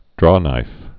(drônīf)